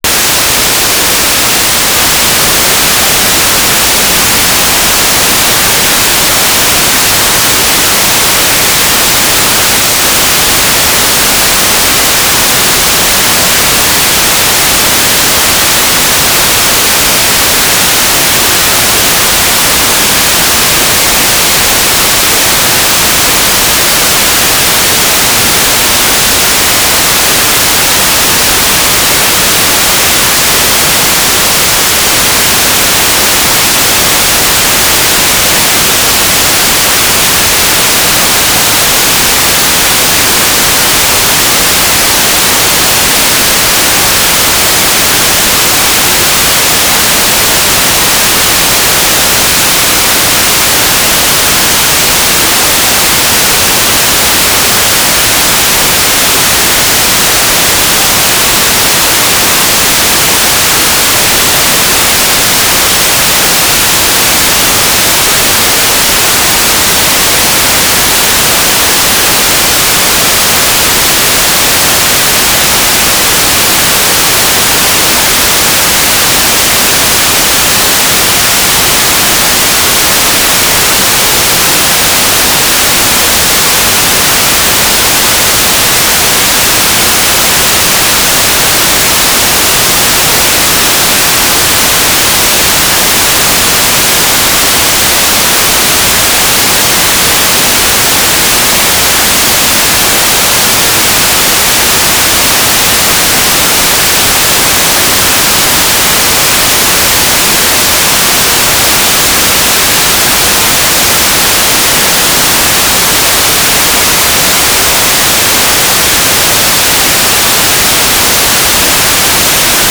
"transmitter_description": "Mode V/U FM - Voice Repeater CTCSS 67.0 Hz",
"transmitter_mode": "FM",